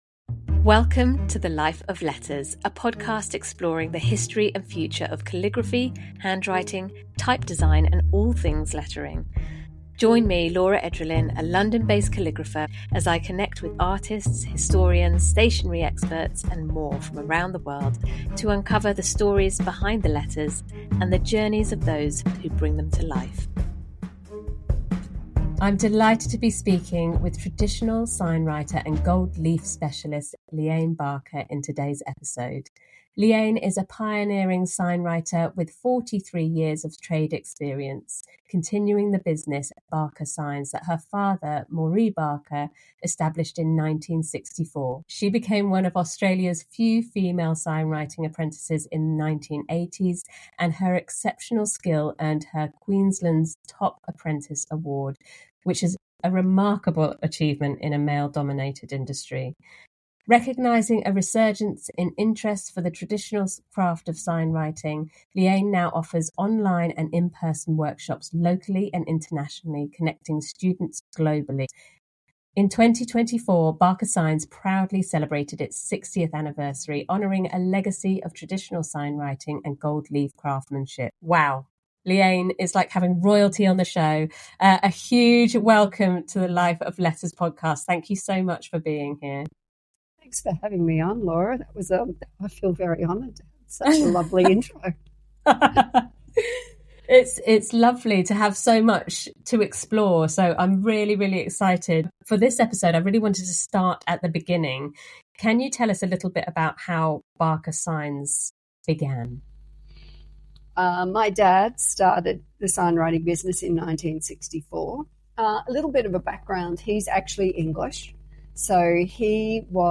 The conversation emphasizes the significance of community and collaboration in the art of sign writing, as well as the legacy of craftsmanship that continues to inspire ar